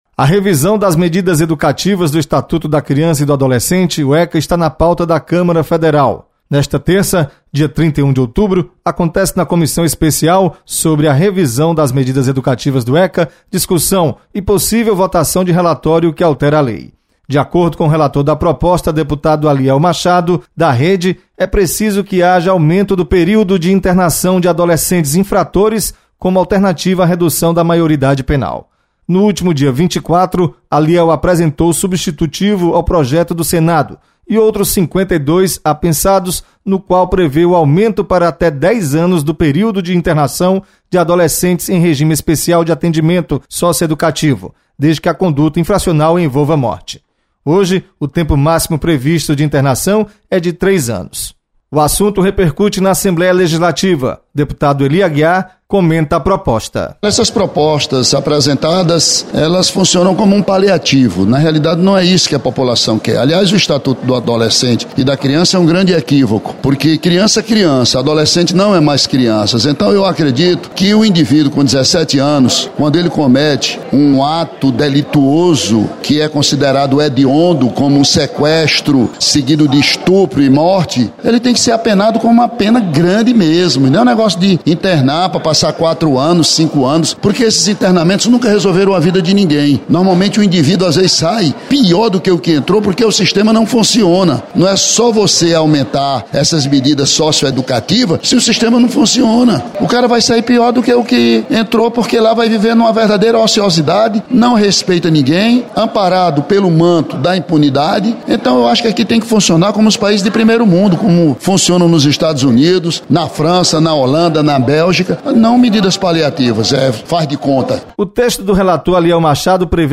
Deputado Ely Aguiar comenta sobre mudanças no Estatuto da Criança e do Adolescente.